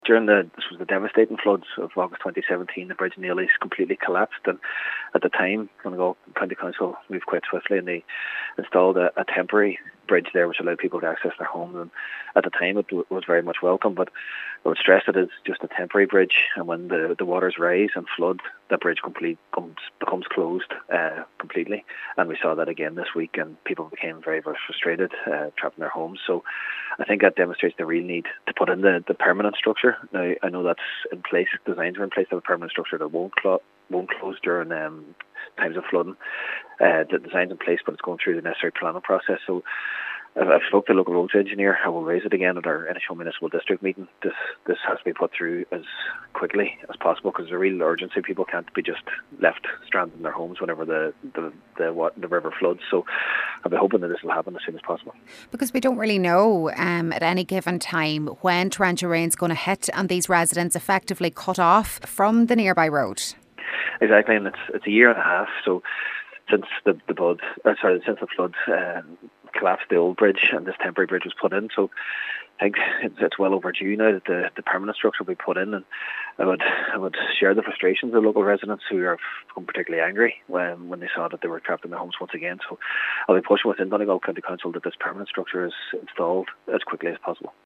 However Cllr Jack Murray says frustration is growing among residents and is urging Donegal County Council to speed up the process: